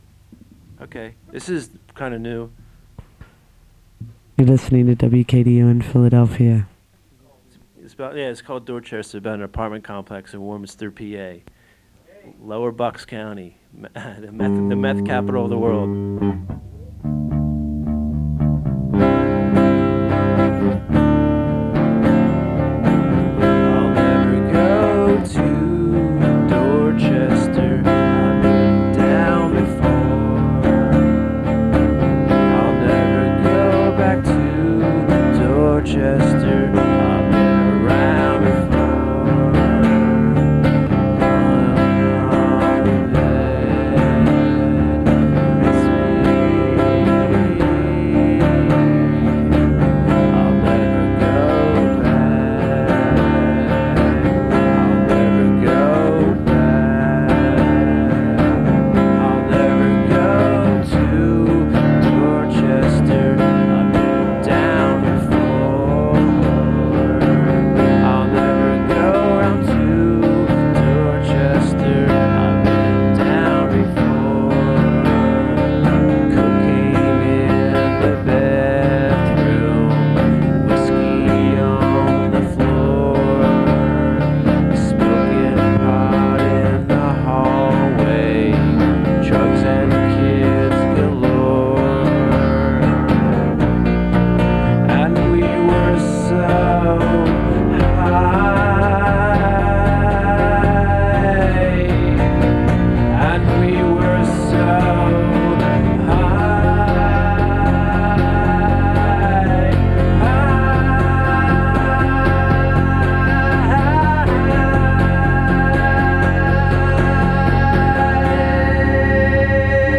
Enjoy the low-fi songs of yearning.